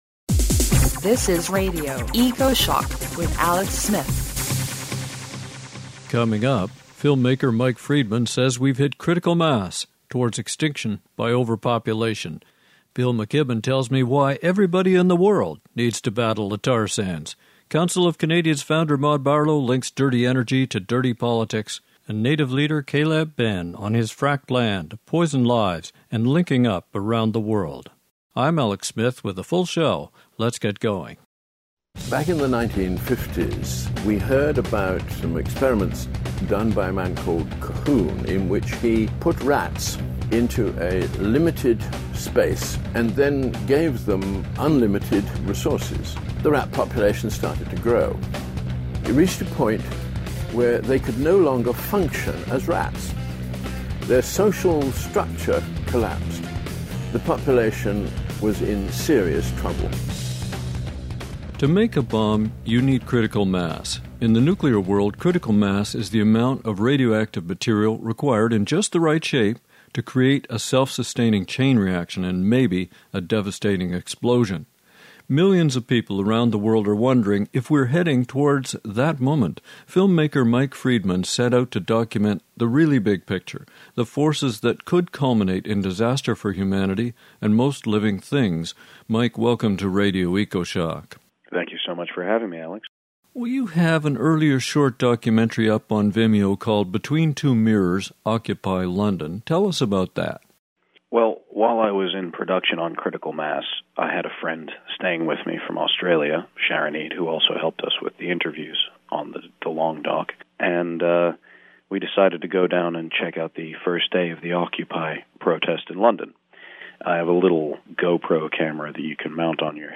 INDIVIDUAL INTERVIEWS